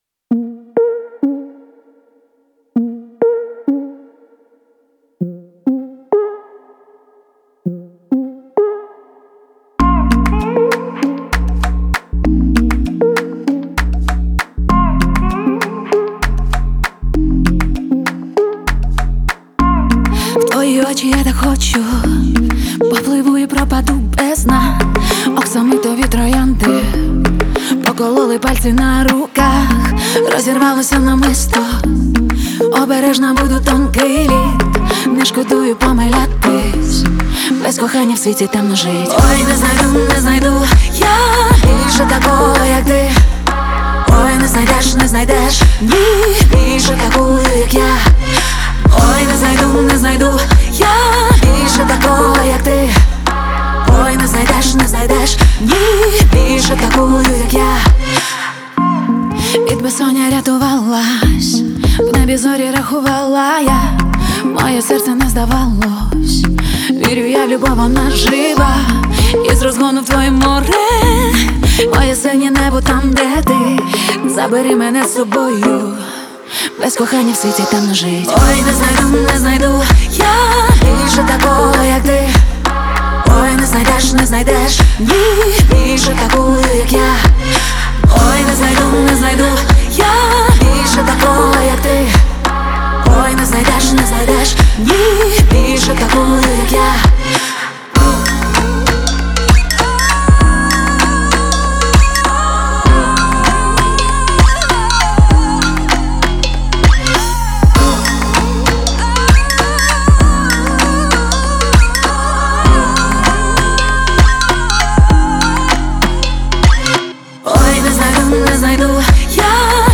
выразительный вокал